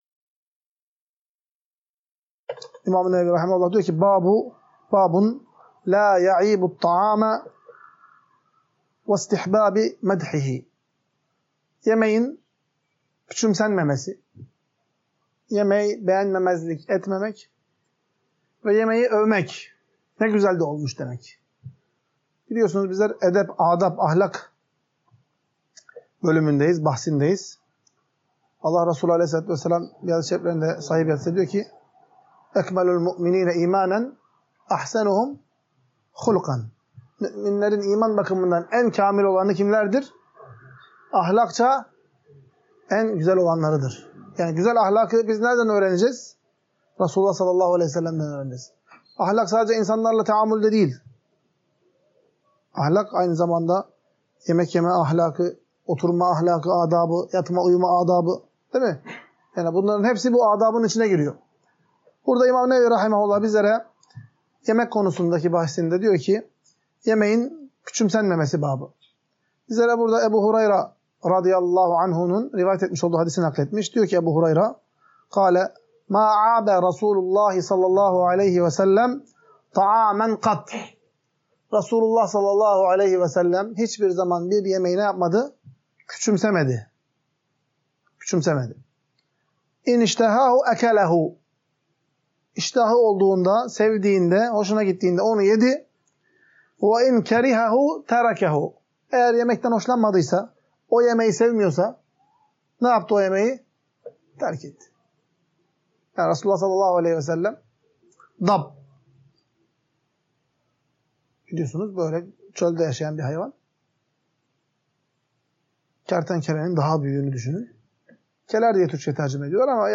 Ders - 3. Bölüm – Oruçlu Iken Bir Yemeğe/ Davete Katılan Kimsenin Yemek Yemediği Takdirde Ne Diyeceği